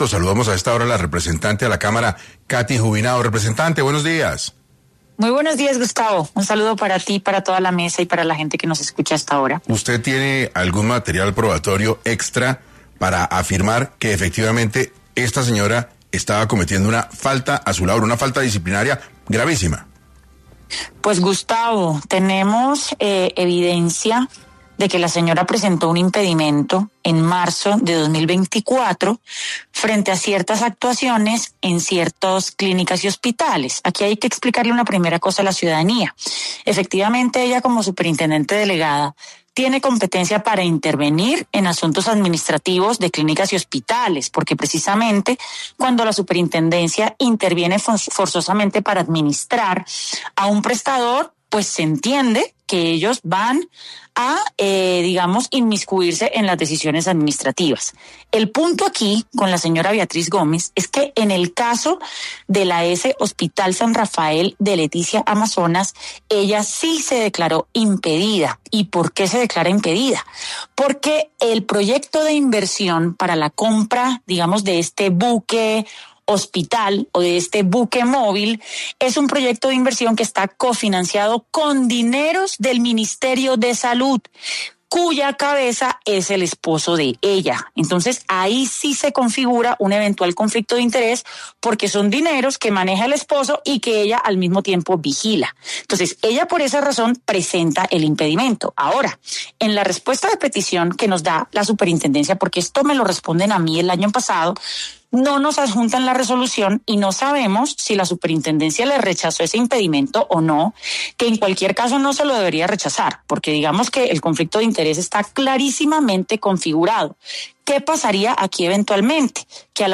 En entrevista para 6AM, la representante Catherine Juvinao, contextualizó y explicó lo que está pasando con Beatriz Gómez, esposa del ministro de Salud, y su escándalo por la ejecución de contratos
En entrevista para 6AM, la senadora Catherine Juvinao, Contextualizó jurídicamente la situación, y explicó cuáles son las posibles faltas en las que incurrió la superintendente y cuál podría ser su futuro en el país.